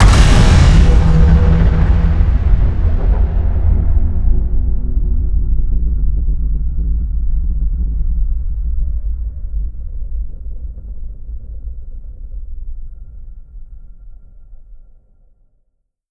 WULA_Unlimit_Penetrating_Beam_Shootingsound.wav